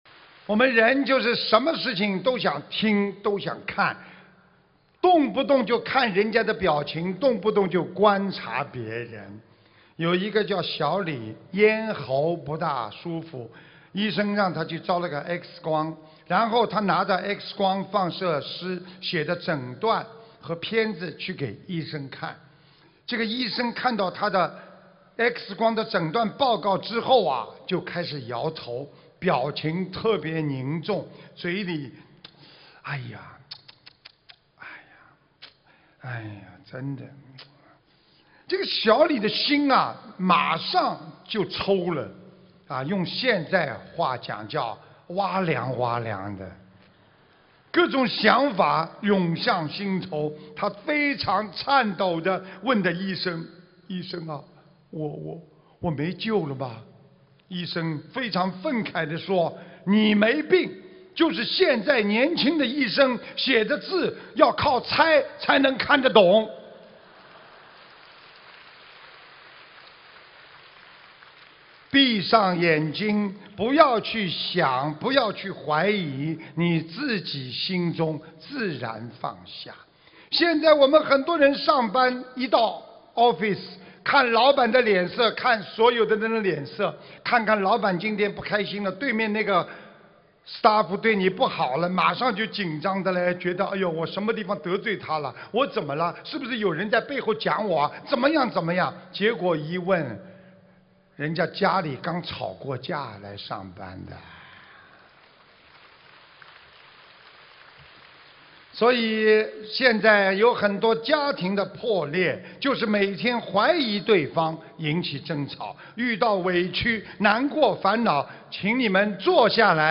目录：法会开示_集锦